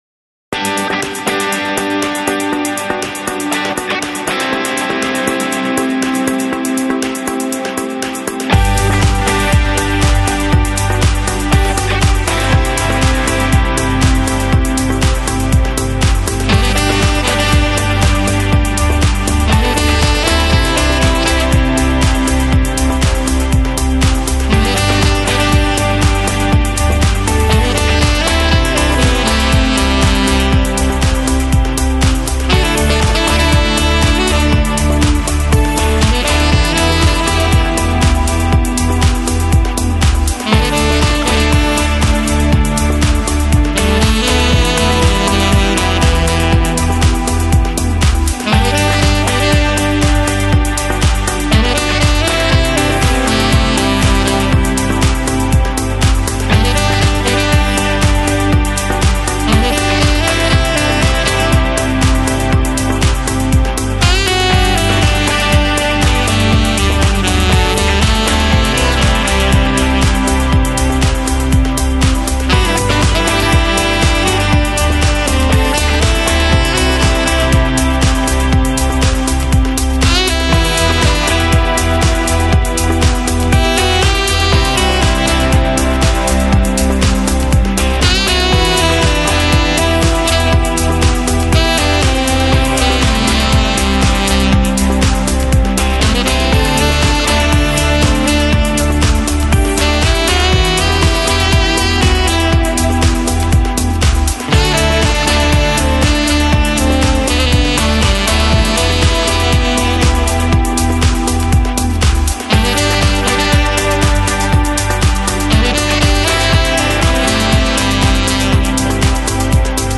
Жанр: Jazz